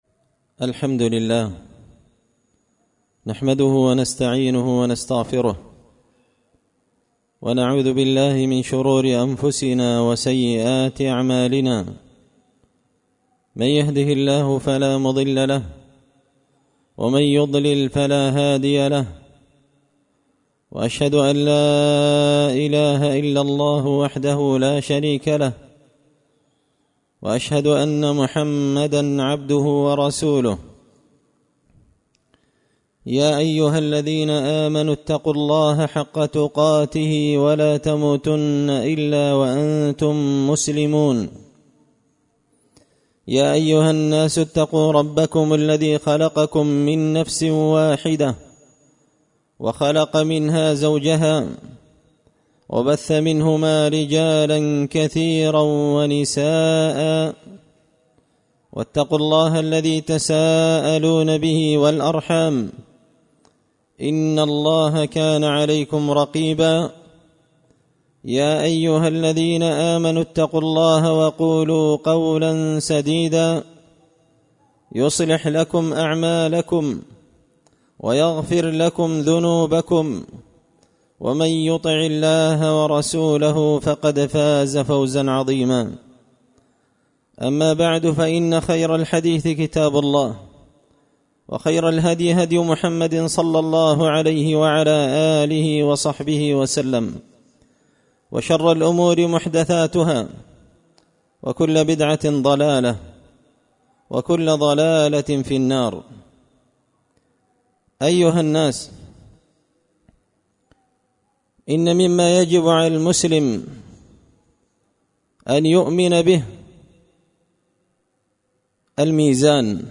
خطبة جمعة بعنوان – الإيمان بالميزان
دار الحديث بمسجد الفرقان ـ قشن ـ المهرة ـ اليمن